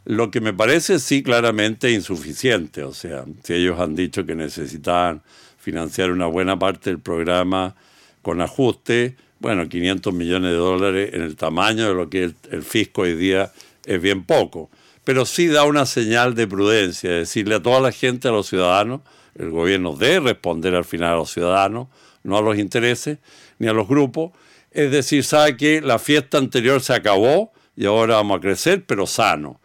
Lo que fue abordado en entrevista con Radio Bío Bío por Eduardo Aninat, el exjefe de las finanzas públicas, bajo el gobierno de Eduardo Frei Ruiz-Tagle